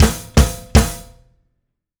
Power Pop Punk Drums Ending.wav